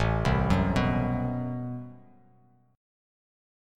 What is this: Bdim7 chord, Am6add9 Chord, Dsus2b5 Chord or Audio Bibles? Am6add9 Chord